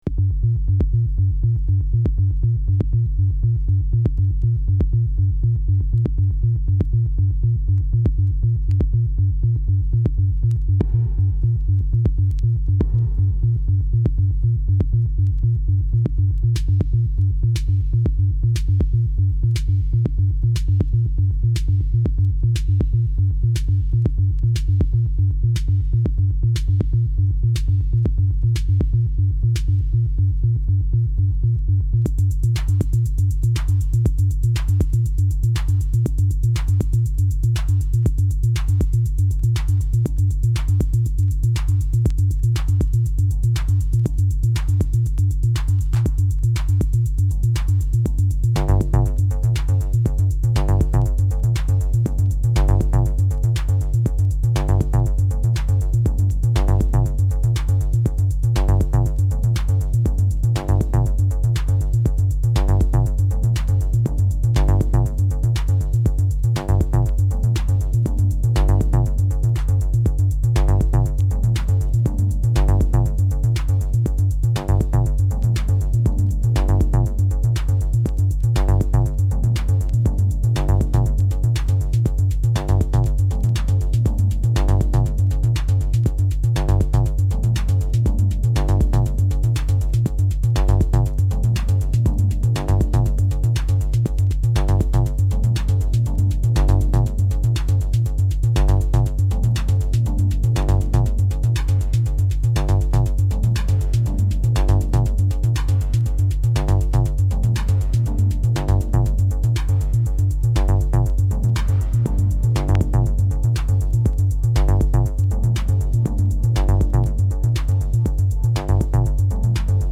House / Techno
ミニマルで耽美的な世界に誘うミラクルトラック。